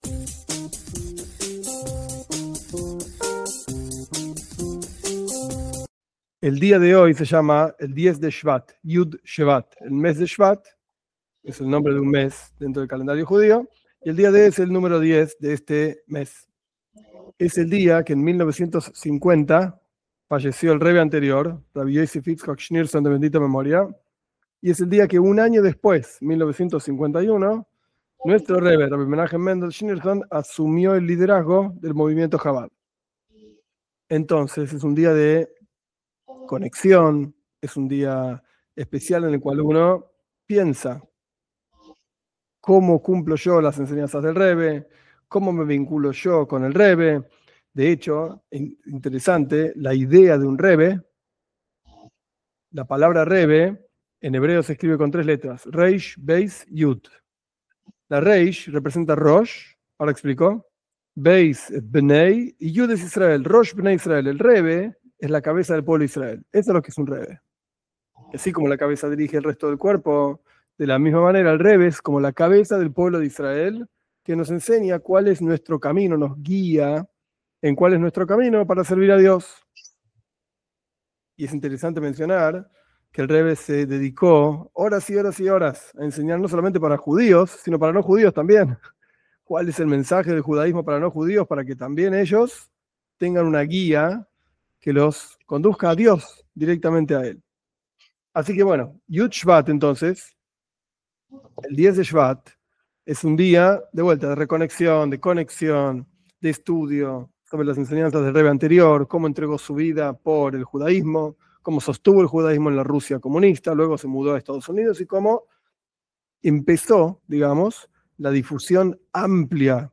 Esta clase es un resumen del discurso jasídico Bati LeGaní - Vine a mi jardín, del Rebe anterior, Rabí Iosef Itzjak Schneerson, de bendita memoria, del año 1950. En honor al 10 de Shvat, día de su fallecimiento.